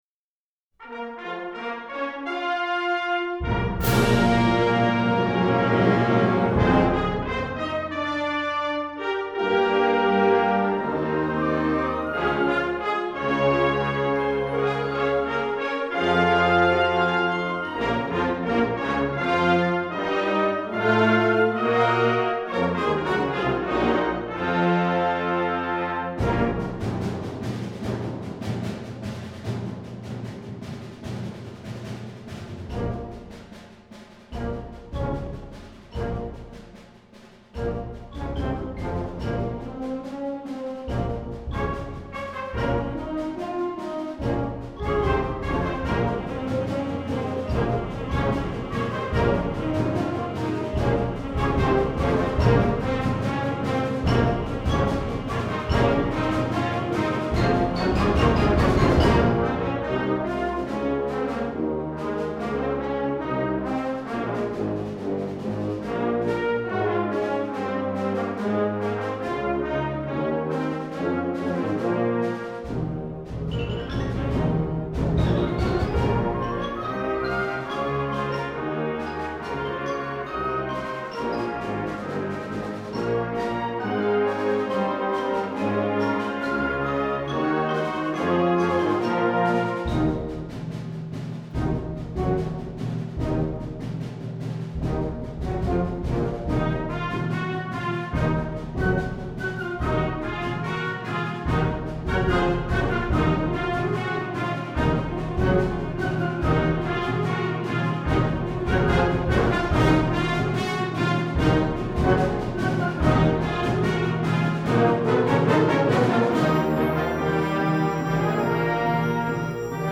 Genre: Band
Flute
Oboe
Bassoon
Tenor Saxophone
F Horn
Tuba
Timpani
Percussion 1 (bells, xylophone, crash cymbals)
Percussion 2 (crash cymbals, bass drum, snare drum)